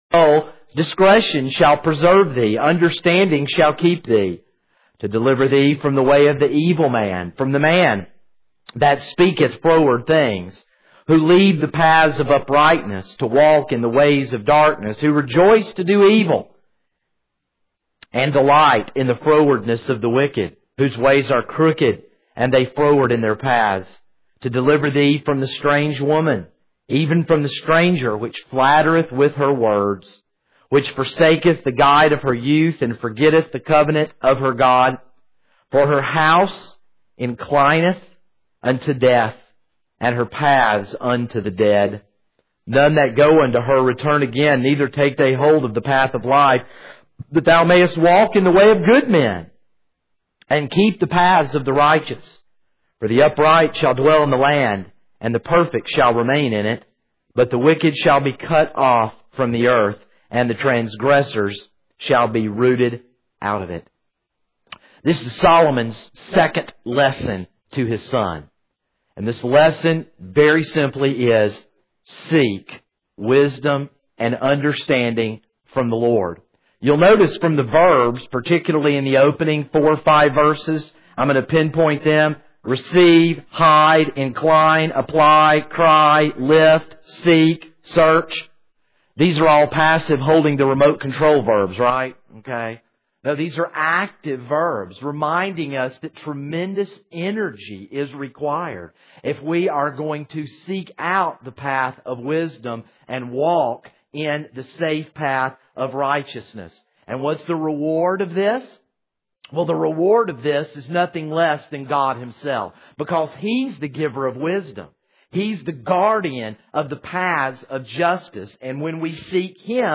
This is a sermon on Proverbs 2:1-22.